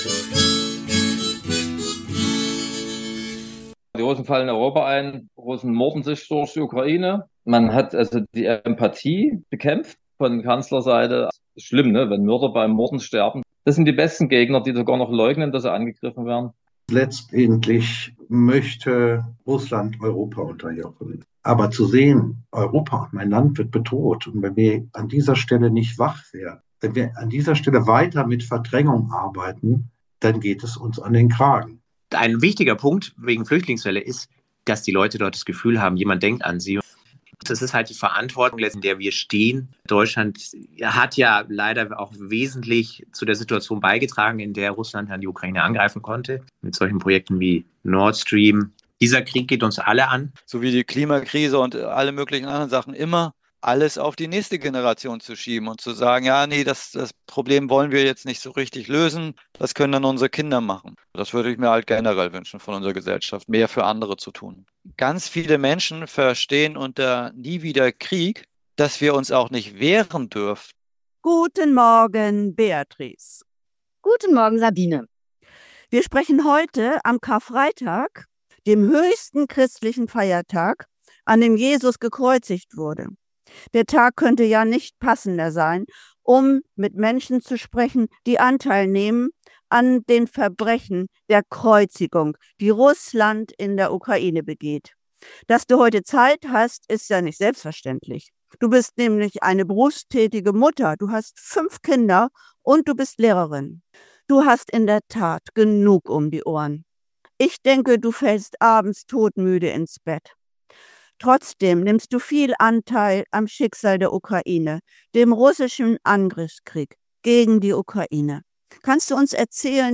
Bürger tun was dazu: Interview